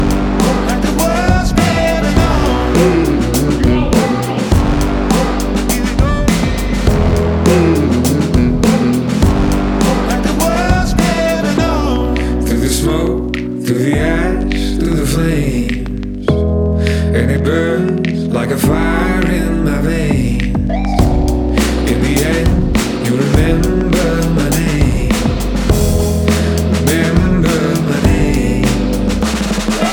Жанр: Поп / Альтернатива